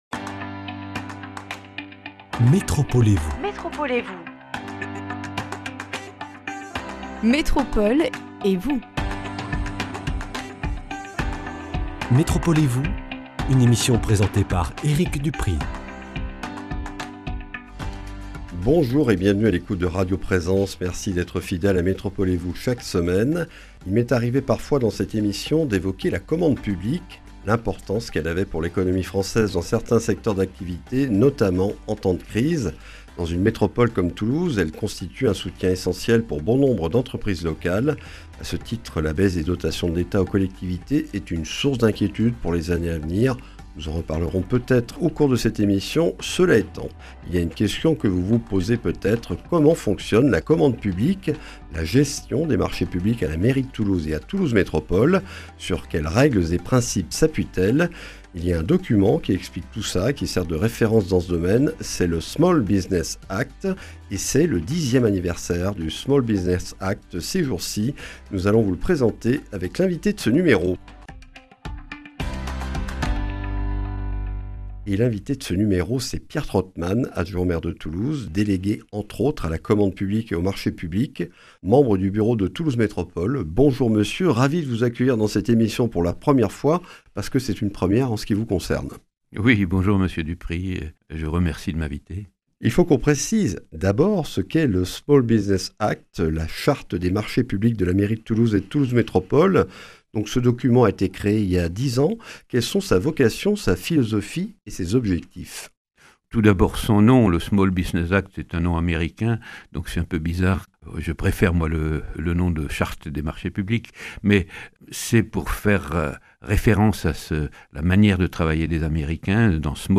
Le Small Business Act, la charte des marchés publics de la mairie de Toulouse et de Toulouse Métropole, célèbre ses 10 ans d’existence ce jeudi 6 mars. Cet anniversaire est l’occasion de présenter ce document, référence pour les engagements des deux collectivités pour la commande publique auprès des PME locales, et de faire un bilan dix ans après sa création. Avec Pierre Trautmann, adjoint au maire de Toulouse, délégué à la commande et aux marchés publics, membre du bureau de Toulouse Métropole.